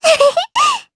Lilia-Vox-Laugh_jp.wav